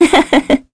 Scarlet-vox-Laugh_kr.wav